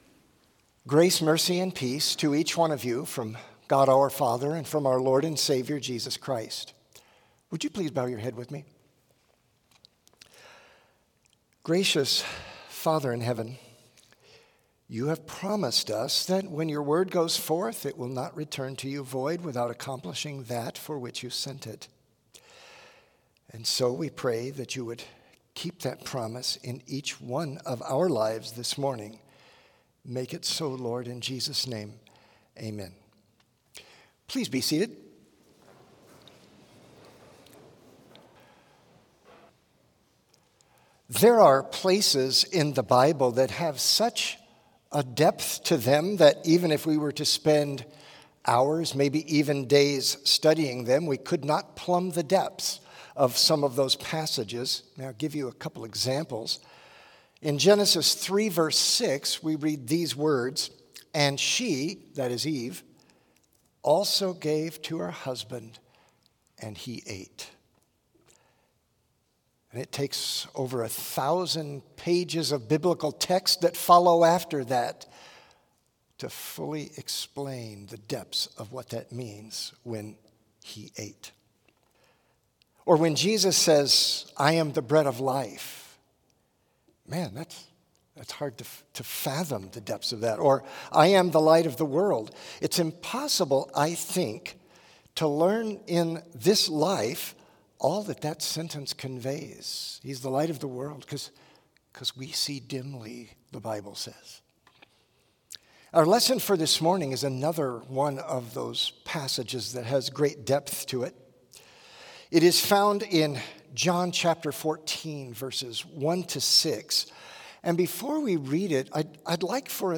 Complete service audio for Chapel - Tuesday, November 26, 2024